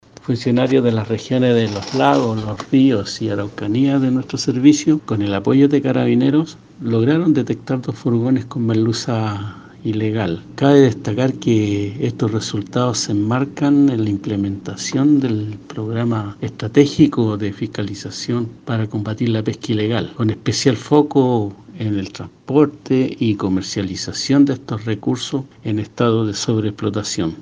Fernando Naranjo, subdirector de Pesquería del Servicio Nacional de Pesca y Acuicultura, detalló el operativo realizado, gracias al cual se incautó el total del recurso